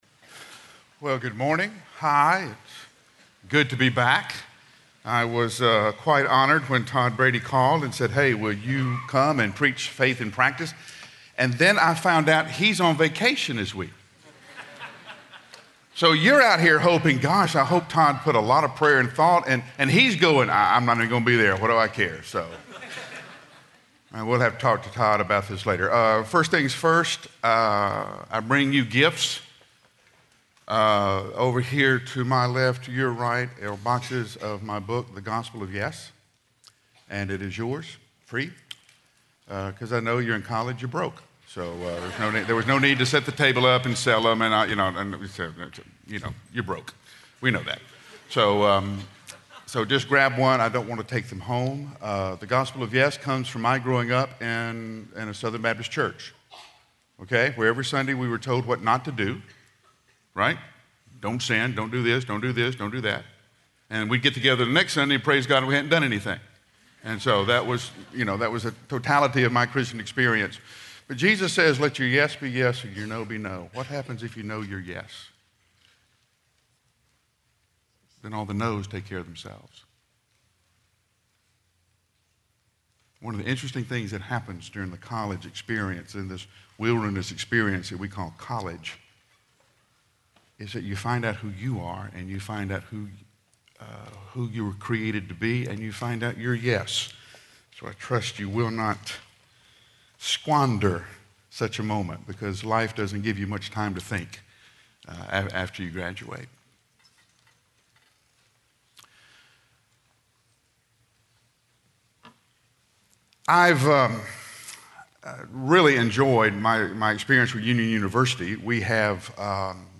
Faith in Practice Chapel